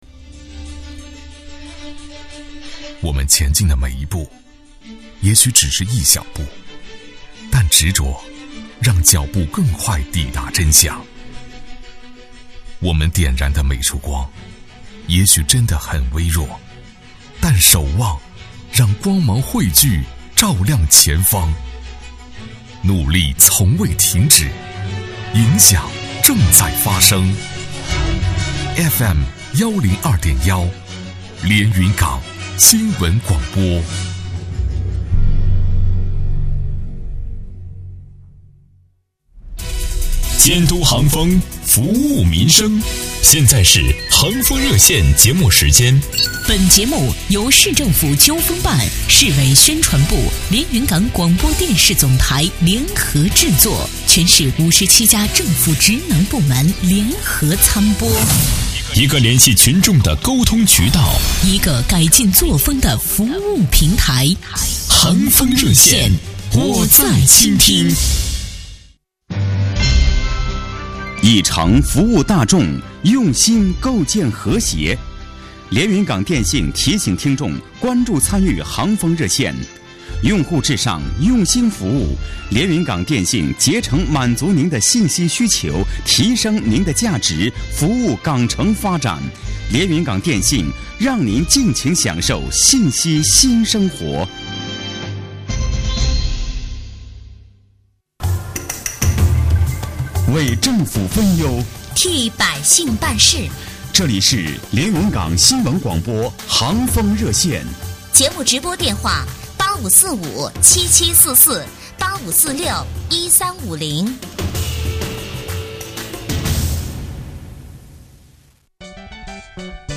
特邀嘉宾 局长、党委书记 赵健康